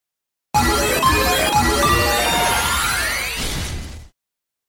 • 霊獣麟ガロ登場音